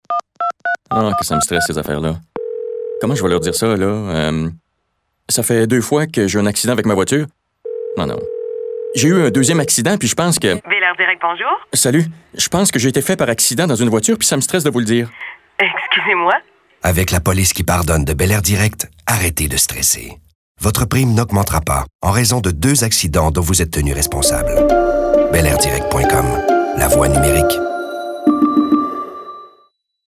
Voix - Publicités :
- La Police qui pardonne (radio),